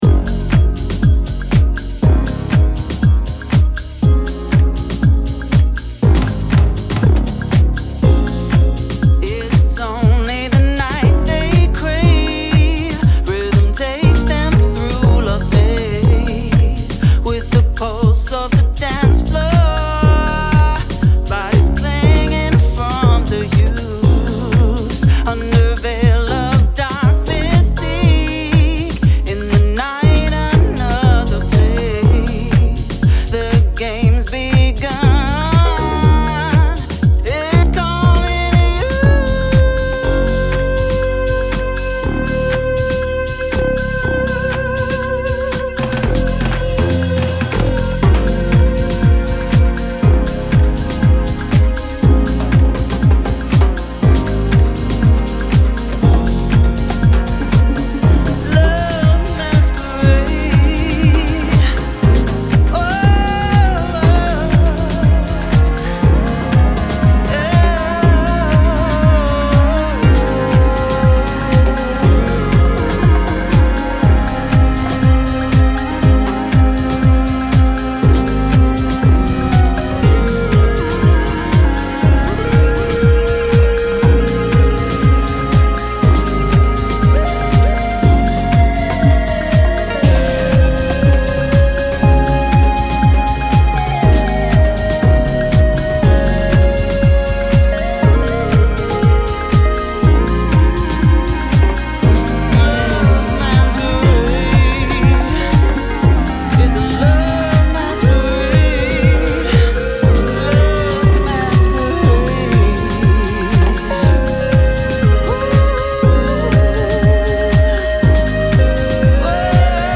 しなやかで温かみある前者ももちろんですが